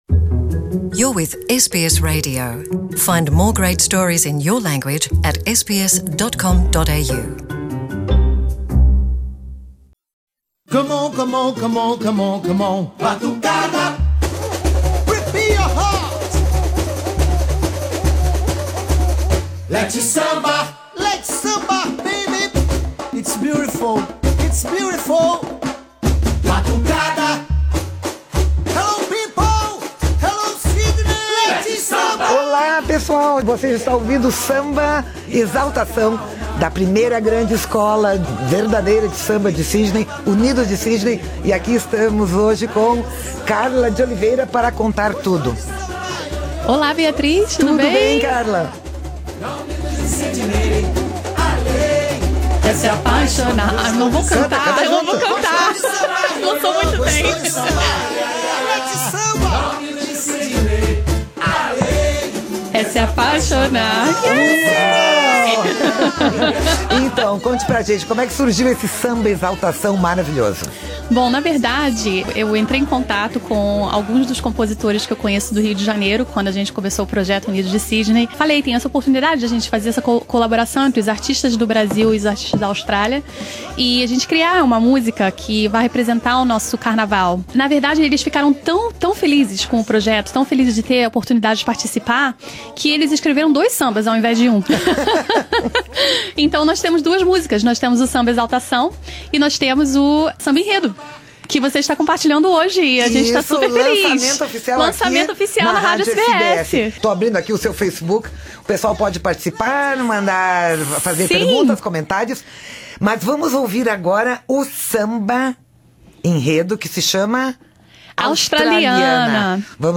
samba-enredo